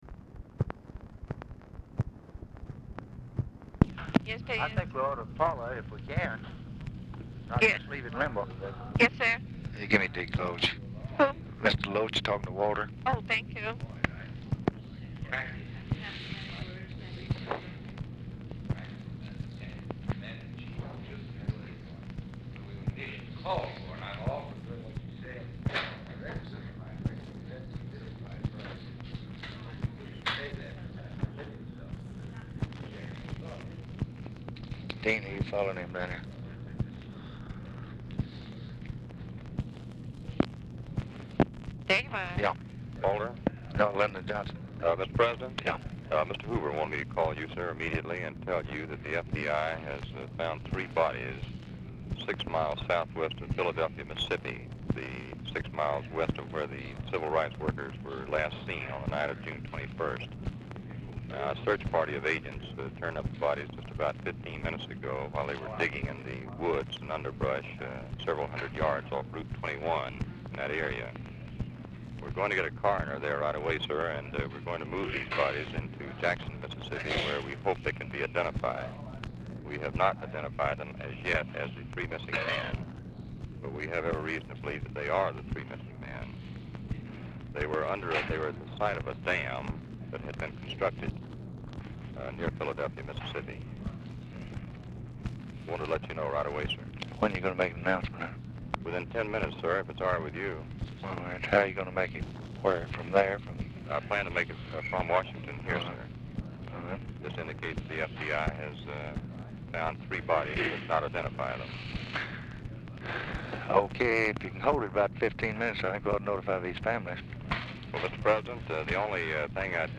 Telephone conversation
OFFICE CONVERSATION PRECEDES CALL
Format Dictation belt